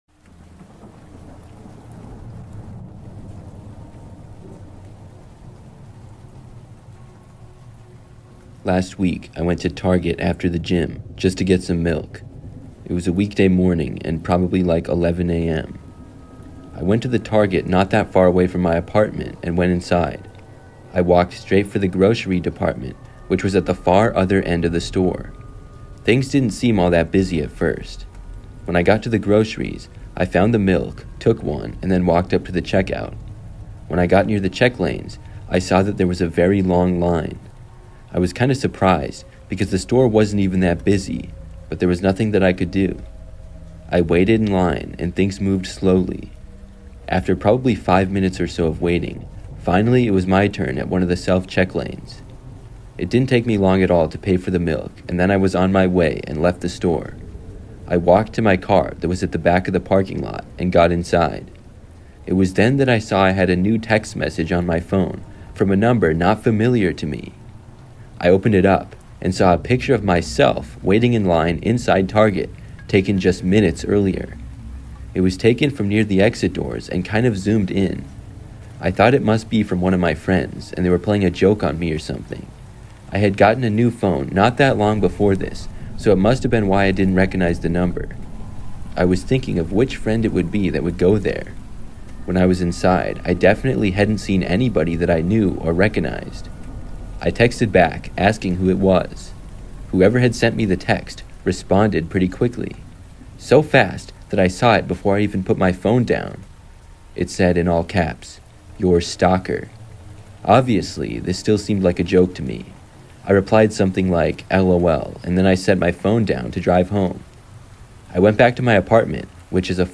True High School Lockdown Horror Stories (With Rain Sounds) That Will Make You Question Safety